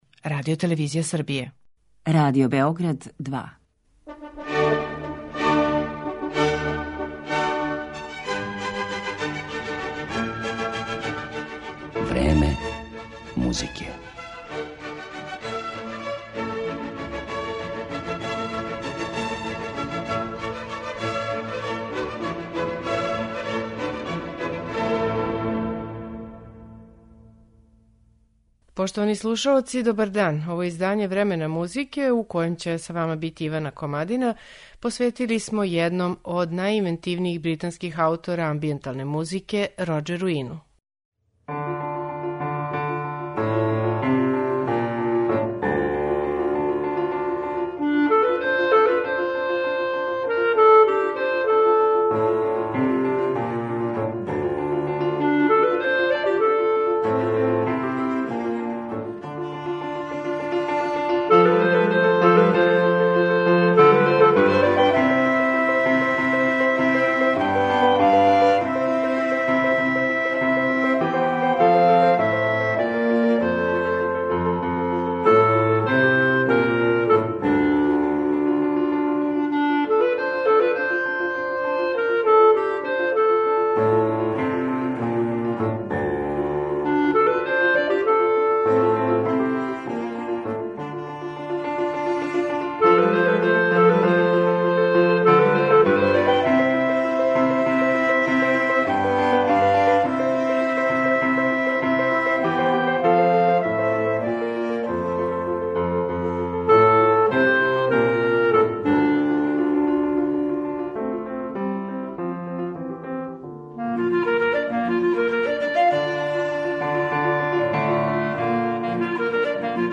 Амбијентална музика